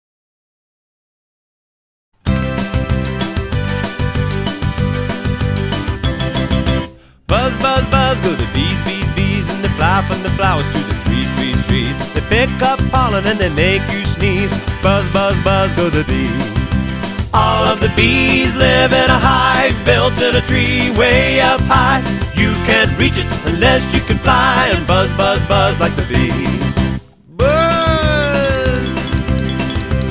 MP3 Demo